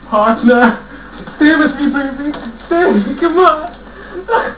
SPEECH FROM THE MOVIE :